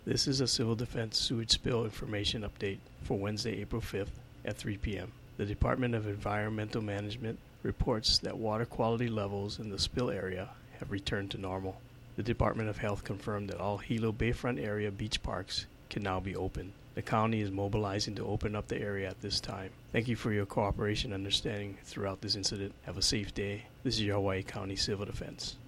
This was the civil defense message issued Wednesday afternoon.